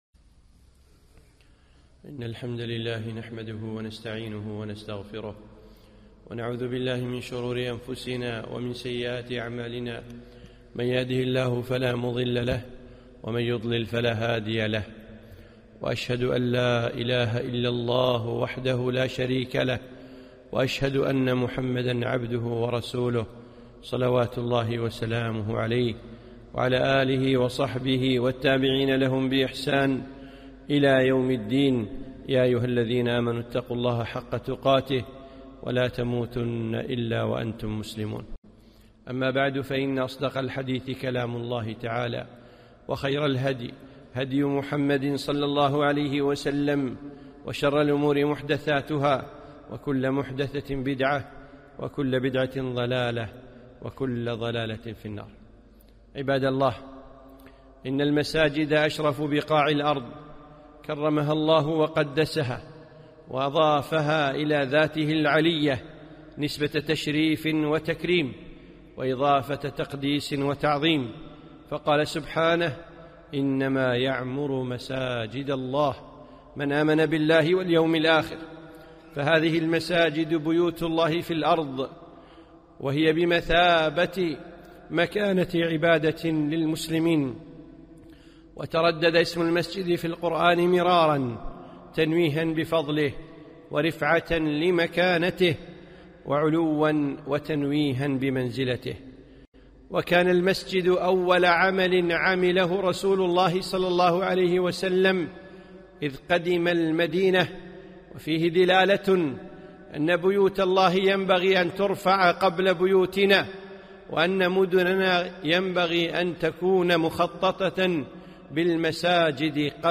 خطبة - مَكَانَةُ الْمَسْجِدِ وَآدَابُهُ